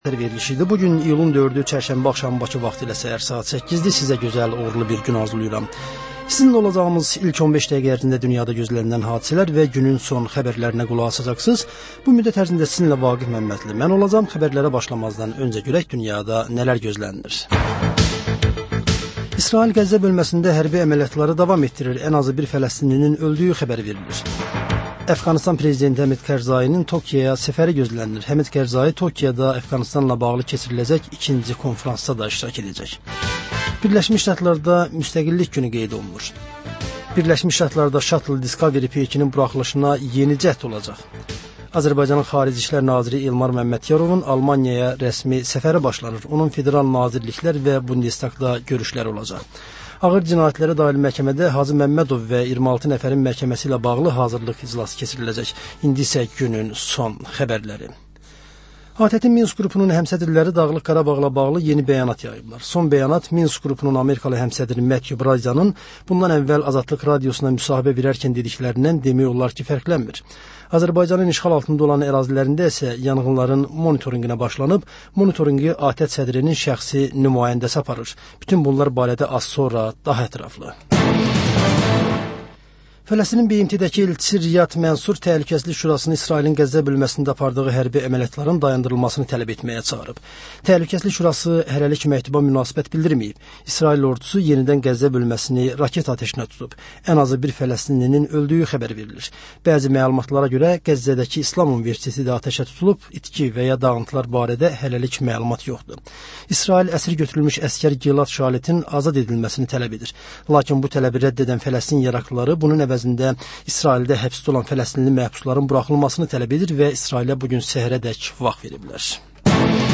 Səhər-səhər, Xəbər-ətər: xəbərlər, reportajlar, müsahibələr. Hadisələrin müzakirəsi, təhlillər, xüsusi reportajlar. Və sonda: Azərbaycan Şəkilləri: Rayonlardan reportajlar.